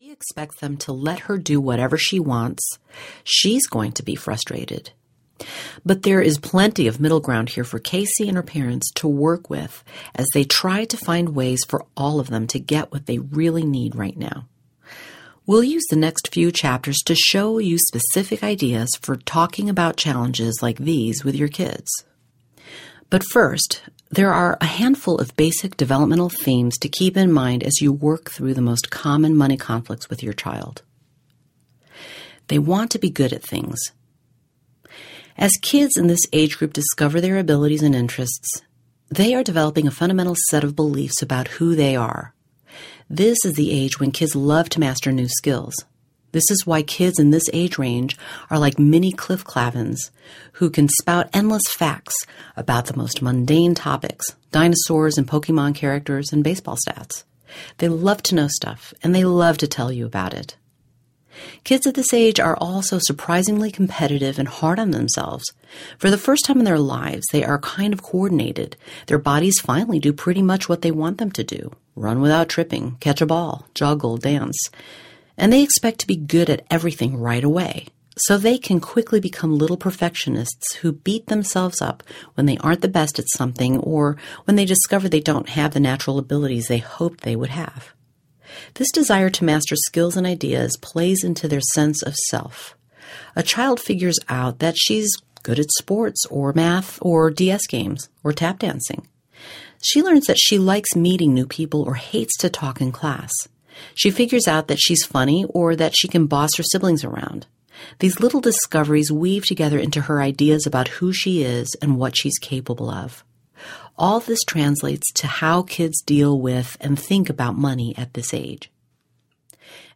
The 5 Money Conversations to Have With Your Kids At Every Age and Stage Audiobook
Narrator
5.6 Hrs. – Unabridged